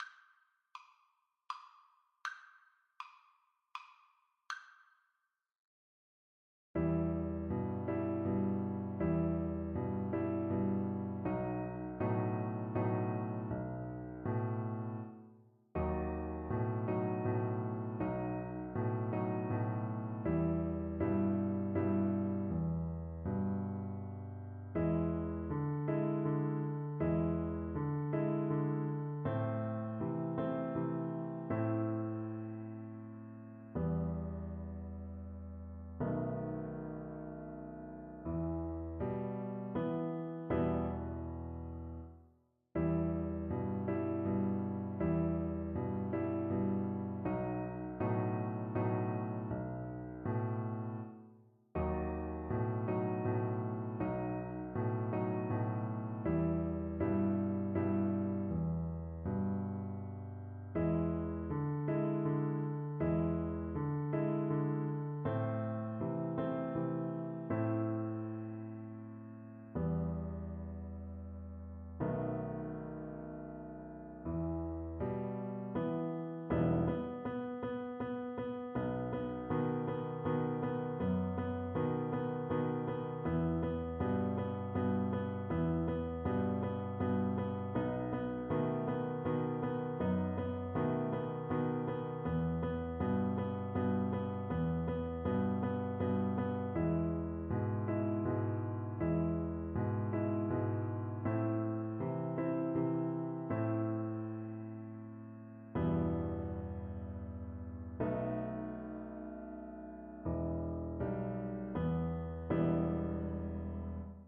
Cello
3/4 (View more 3/4 Music)
E minor (Sounding Pitch) (View more E minor Music for Cello )
Traditional (View more Traditional Cello Music)
Venezuelan